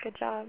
Seven prosodic variants of good job (au files):
neutral.au